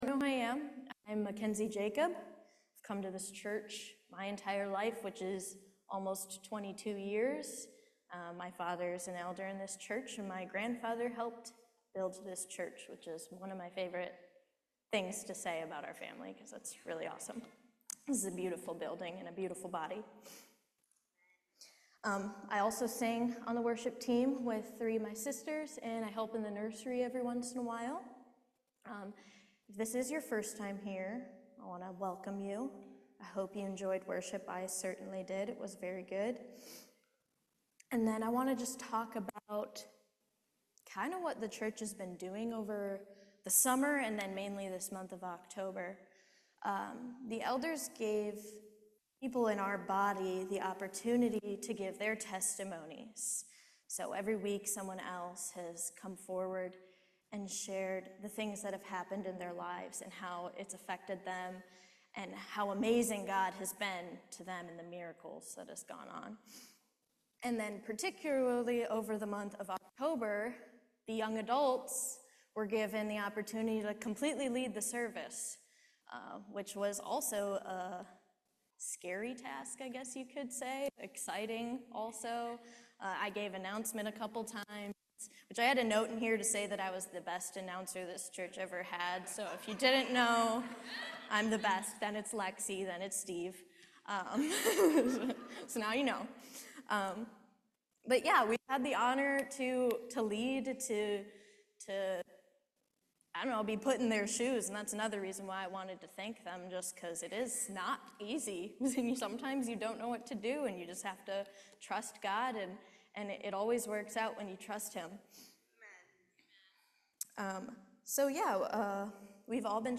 Service Type: Main Service